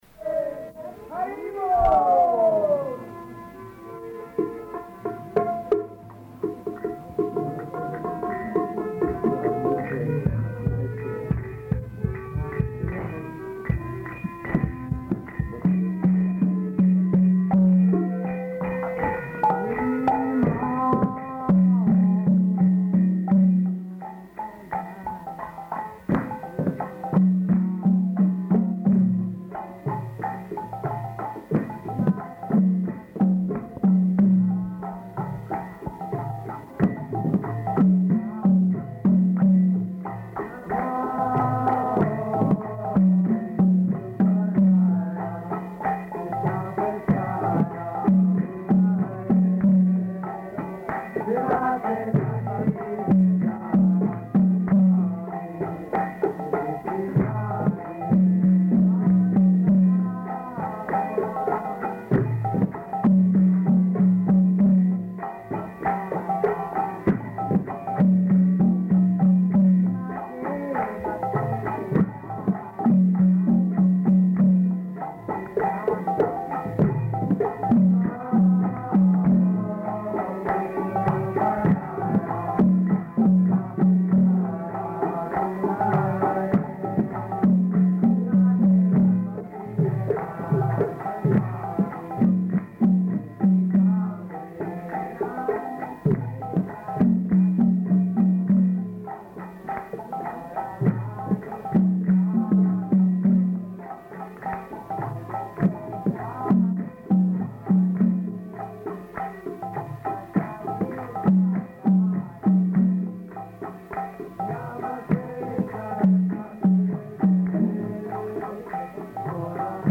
Lecture in Hindi
Type: Lectures and Addresses
Location: Allahabad
[ kīrtana ]